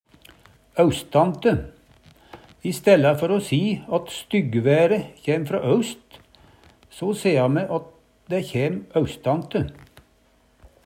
austante - Numedalsmål (en-US)